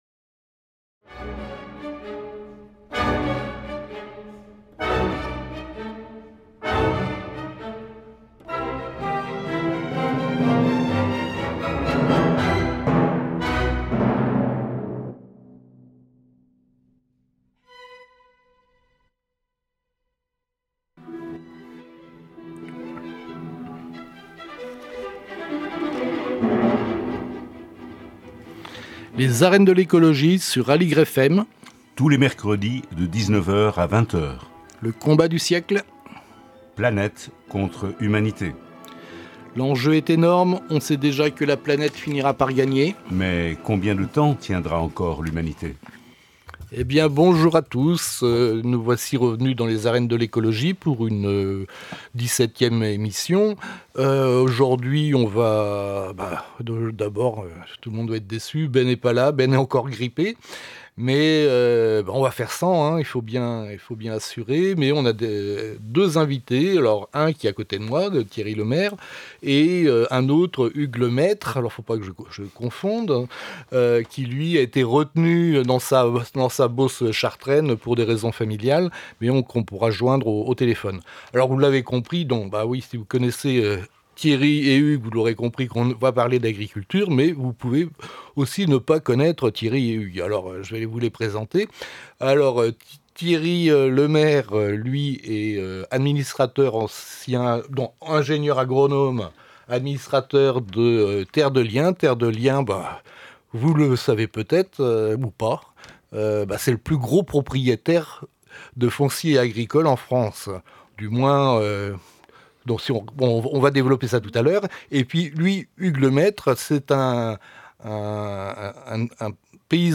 Aujourd'hui, nous recevons deux invités, témoins atypiques mais néanmoins significatifs d'un monde agricole en profond bouleversement économique, culturel et démographique.